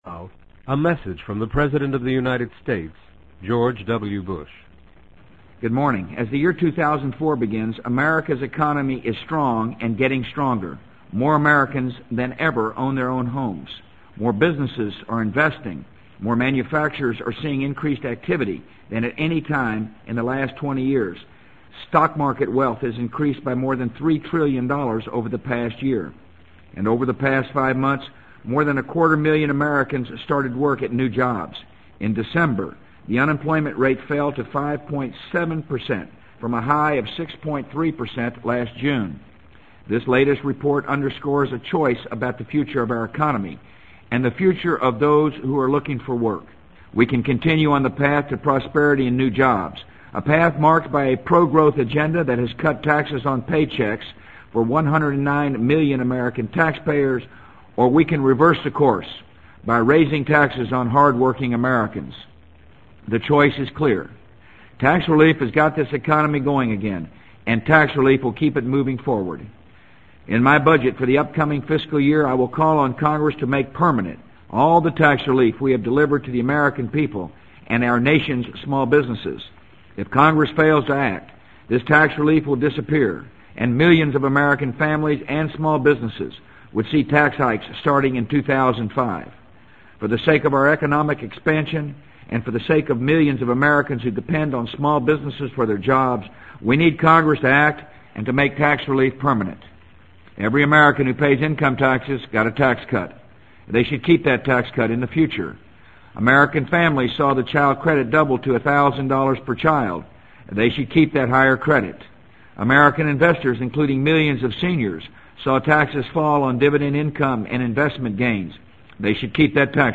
【美国总统George W. Bush电台演讲】2004-01-10 听力文件下载—在线英语听力室